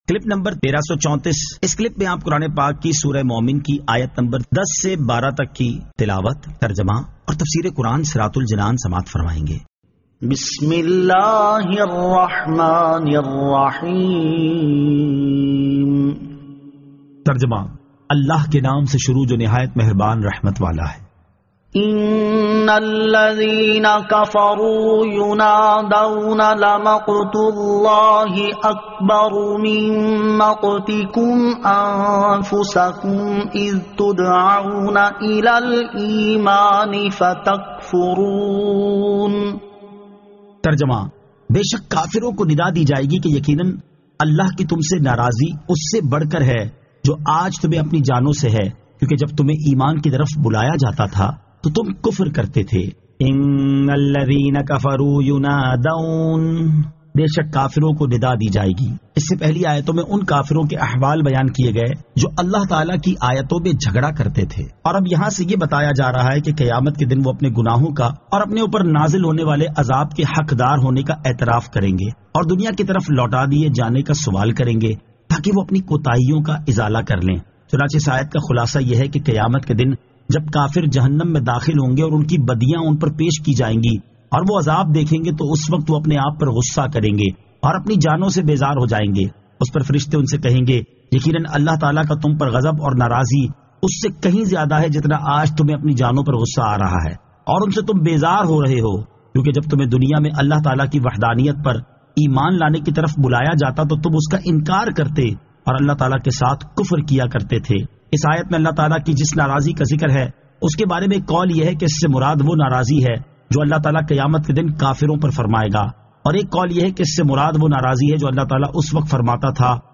Surah Al-Mu'min 10 To 12 Tilawat , Tarjama , Tafseer